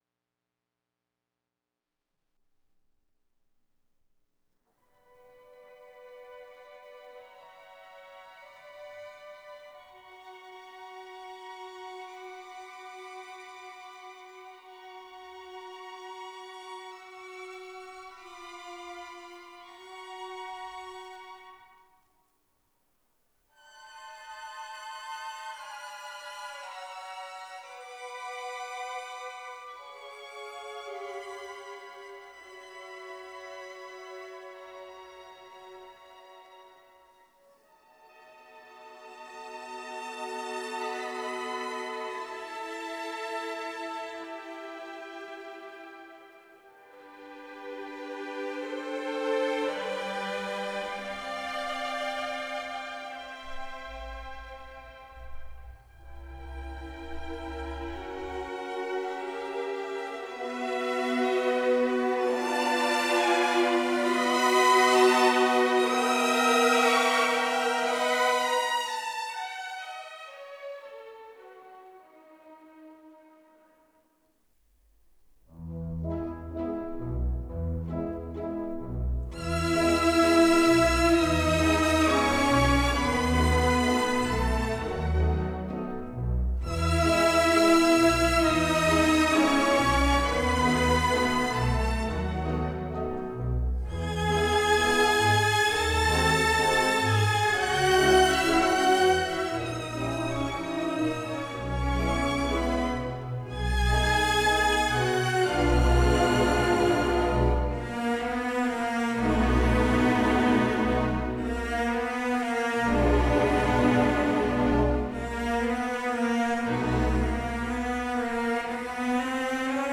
Kingsway Hall